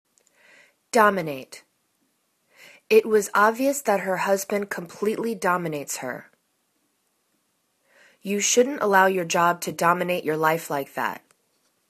dom.i.nate    /'domina:t/    v